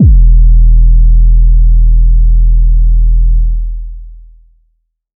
• phonk kicks oneshot 2 - 808 E.wav
Specially designed for phonk type beats, these nasty, layered 808 one shots are just what you need, can also help designing Hip Hop, Trap, Pop, Future Bass or EDM. Enjoy these fat, disrespectful 808 ...
phonk_kicks_oneshot_2_-_808_E_yxE.wav